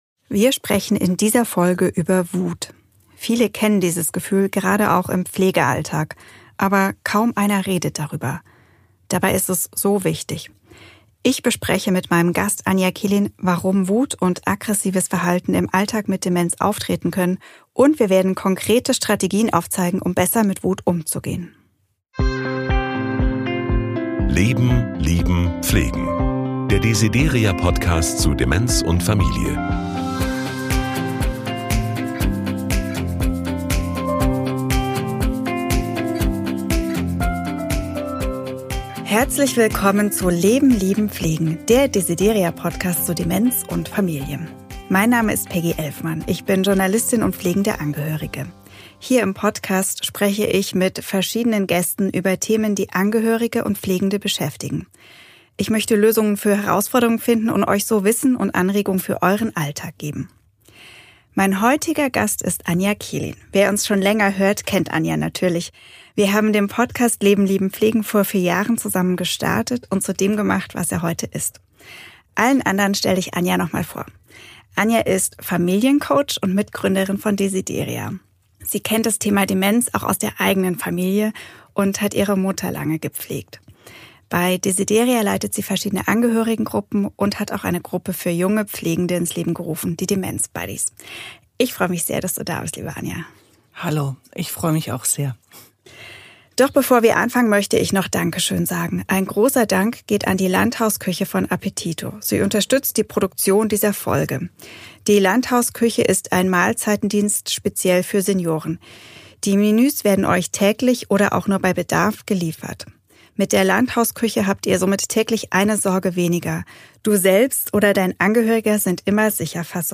Gemeinsam mit anderen Angehörigen, Betroffenen und Fachleuten spricht sie über die täglichen Herausforderungen, hilfreiche Strategien und Möglichkeiten, sich selbst vor Überlastung und Erschöpfung zu schützen.